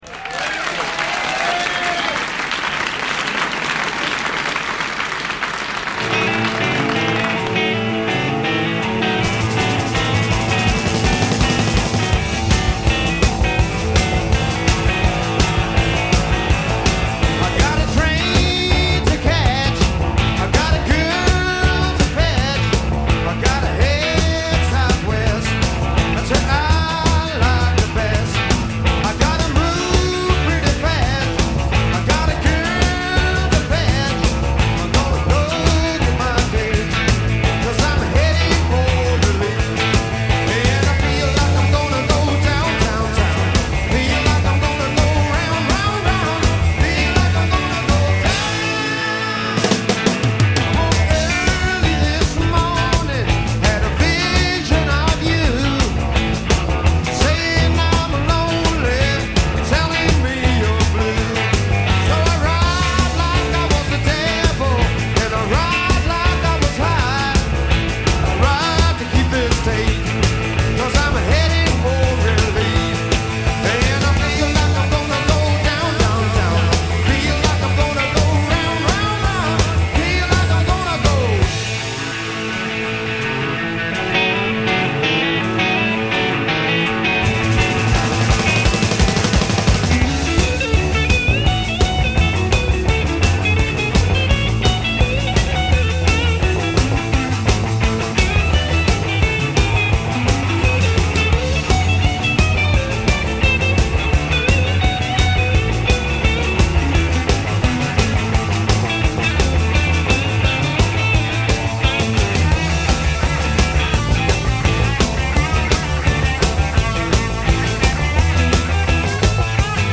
Live from The Paris Theatre, London
with his backing band
Pub Rock
a reminder of what kickass Rock n’ Roll sounded like.